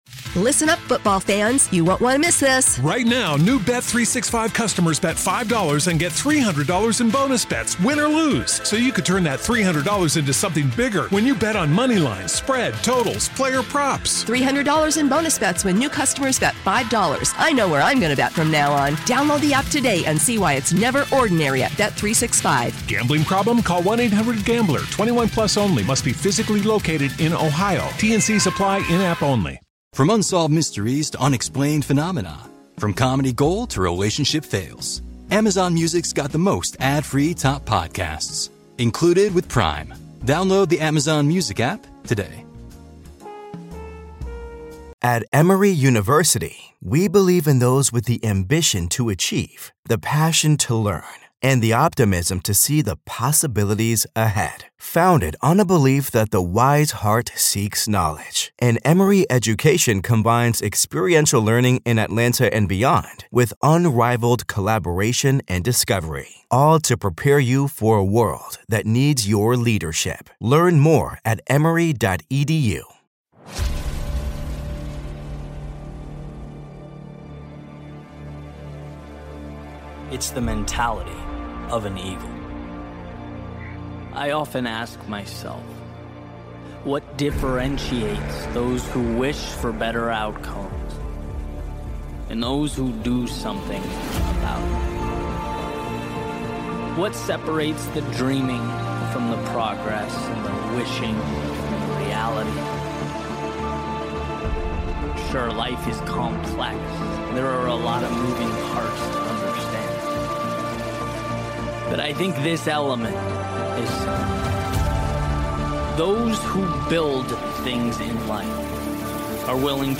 Building a Life Beyond Limits - Powerful Motivational Speech